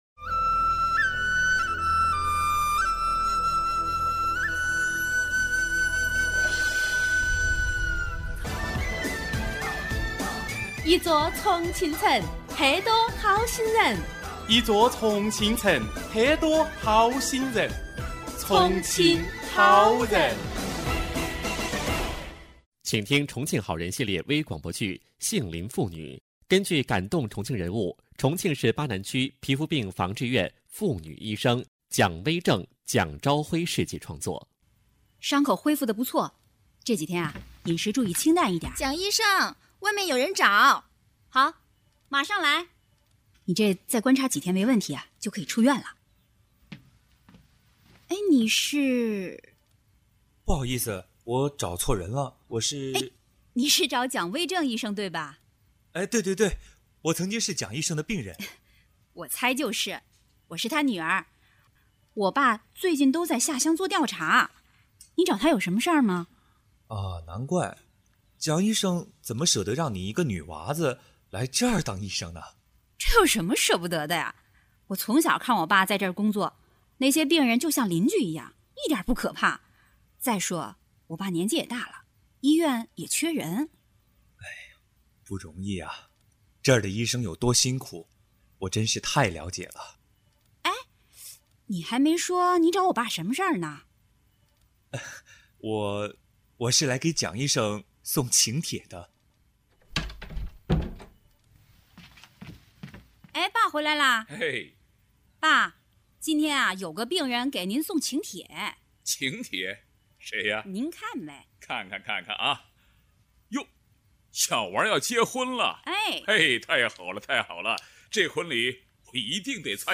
中国广播剧场 首页 > 微广播剧 > 杏林父女